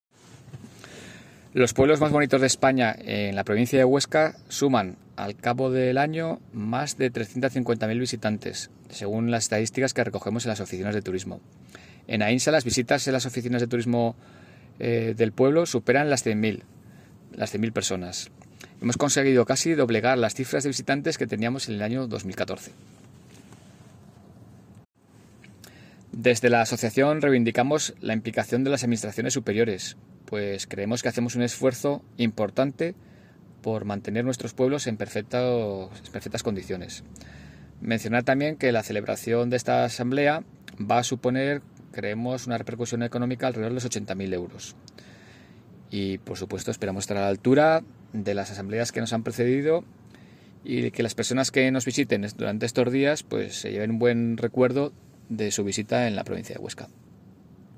Los alcaldes de los tres pueblos presentan en la Diputación Provincial de Huesca la XII asamblea anual, que por primera vez se celebra en Aragón
Declaraciones Enrique Pueyo